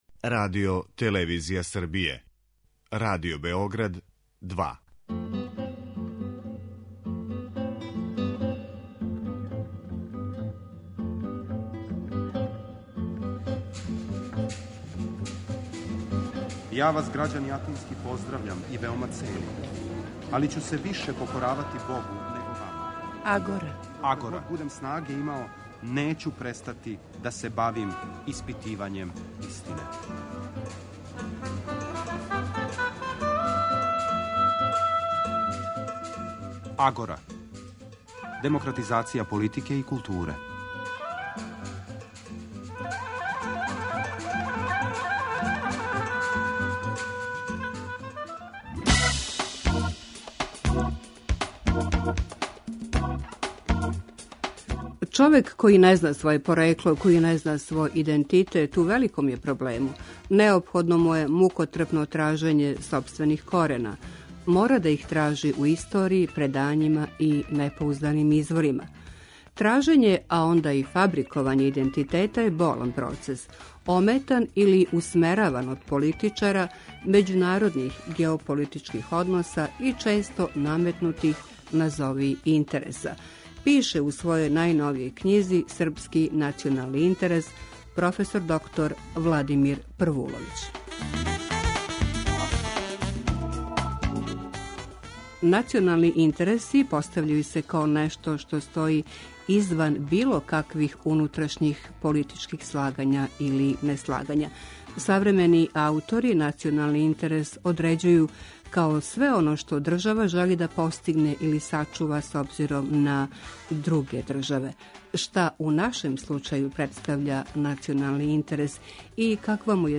Радио-магазин који анализира феномене из области политичког живота, филозофије, политике и политичке теорије.